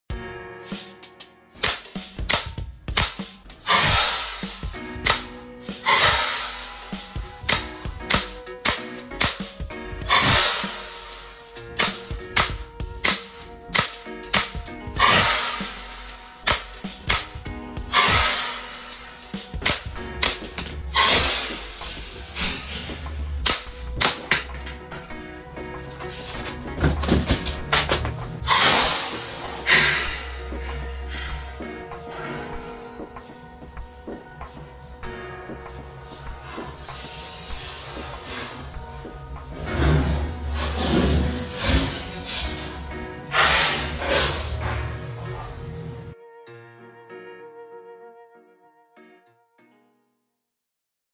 Neste vídeo, trazemos um confronto direto e devastador entre uma latinha de água tônica e a poderosa Carabina de Pressão Jade 5.5. Sem introduções ou apresentações, vamos direto à ação: um tiro após o outro na latinha até que ela seja completamente destruída e o cenário desmorone.
Em seguida, a Carabina Jade 5.5 entra em ação, disparando tiros precisos e potentes.